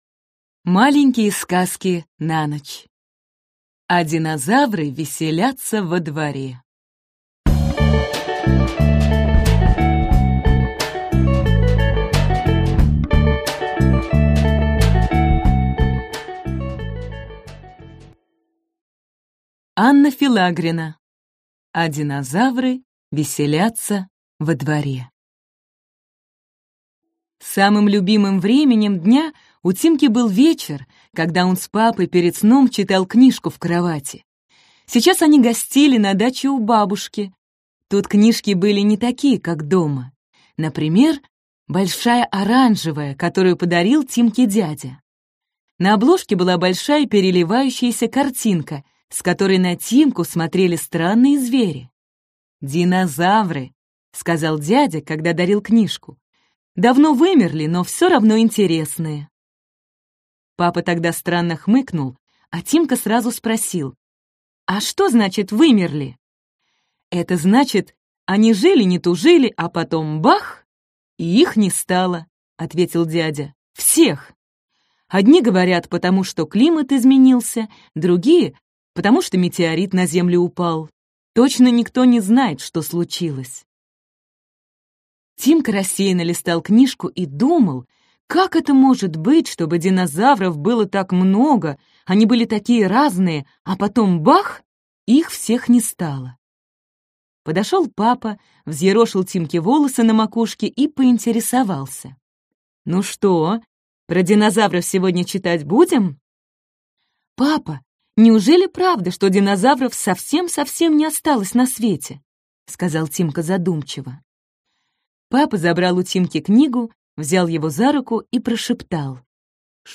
Аудиокнига А динозавры веселятся во дворе!